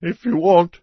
PeasantYesAttack2.mp3